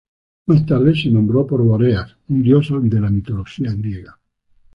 /ˈdjos/